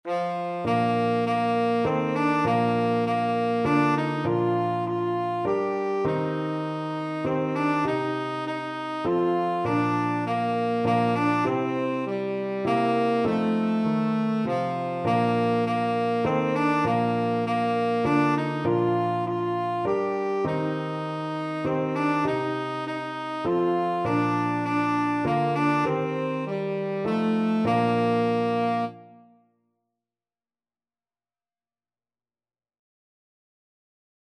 Tenor Saxophone version
Christmas
3/4 (View more 3/4 Music)